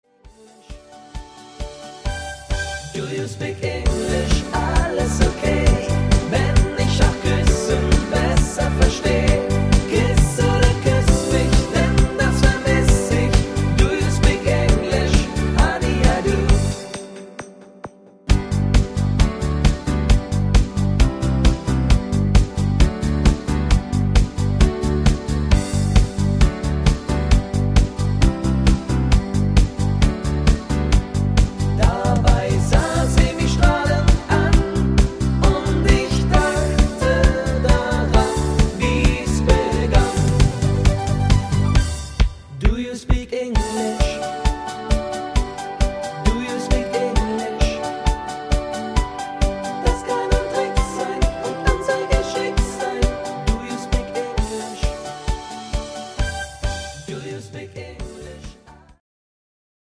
Partymix